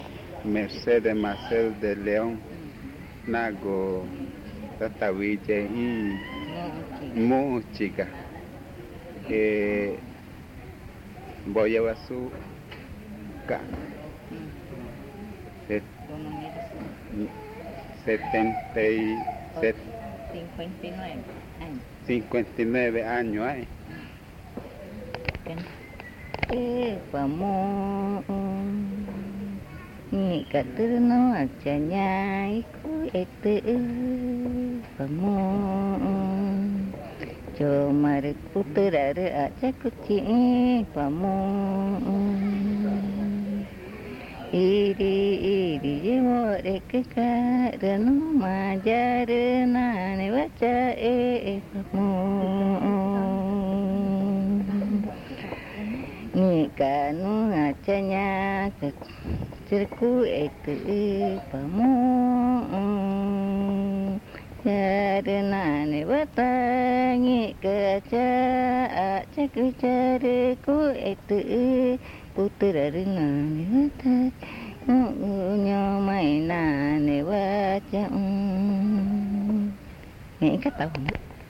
Canto del picaflor
Pozo Redondo, Amazonas (Colombia)